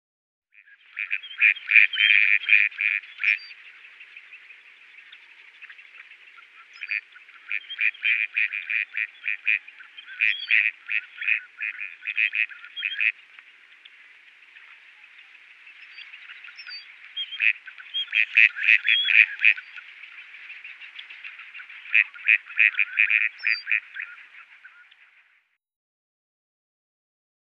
Gadwall | Sneak On The Lot
Gadwall Quacks. Low, Rhythmic Nasal Bird Quacks In The Foreground With Other Birds Chirping In The Background.